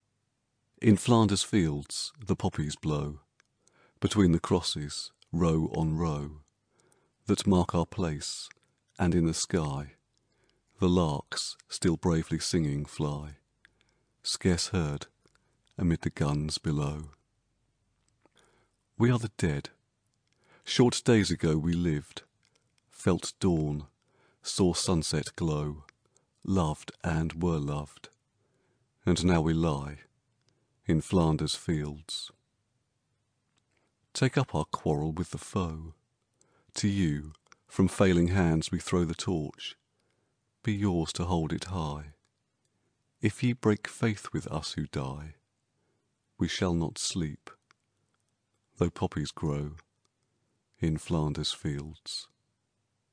"개양귀비 들판에서" 낭독